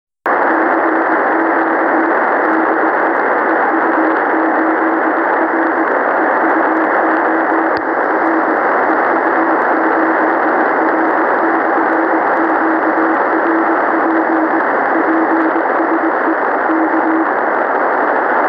Приём был очень шумным.
В качестве антенны - проводок, без проводка на встроенный штырек ещё меньше можно услышать.